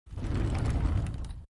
SlidingDoorSFX.wav